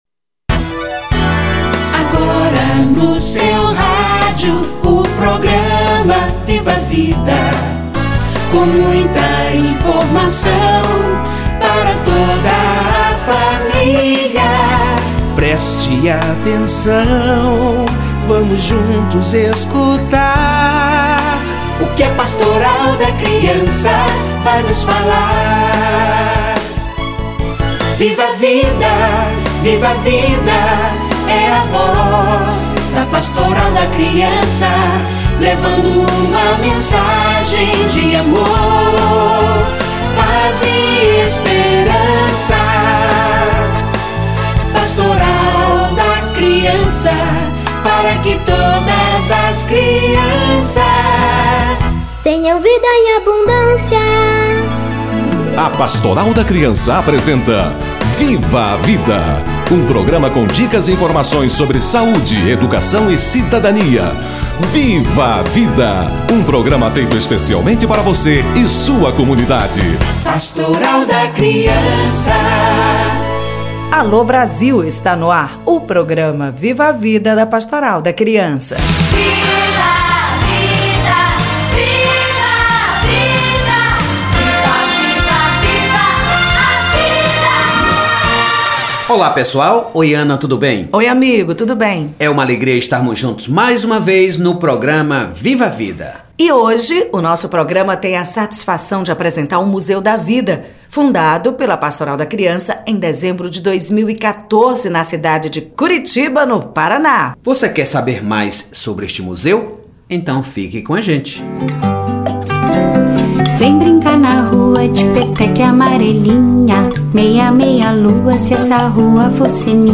O brincar no Museu da Vida - Entrevista